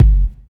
60 KICK 2.wav